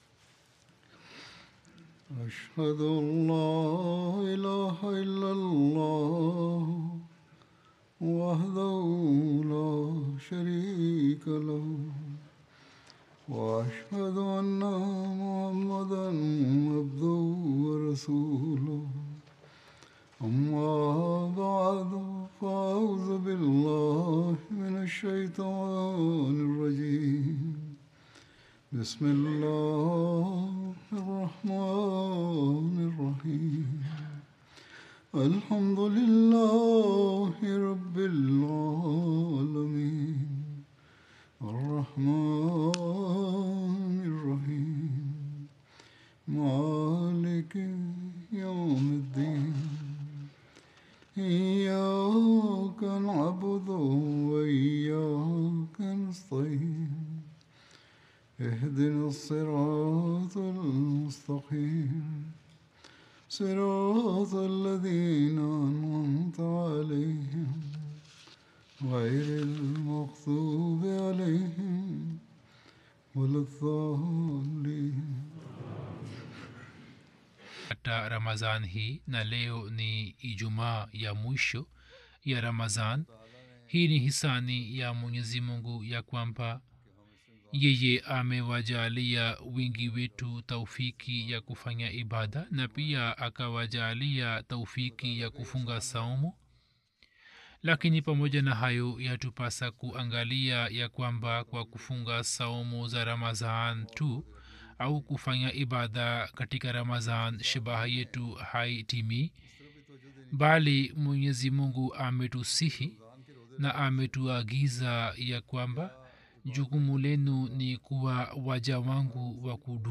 Swahili Friday Sermon by Head of Ahmadiyya Muslim Community
Swahili Translation of Friday Sermon delivered by Khalifatul Masih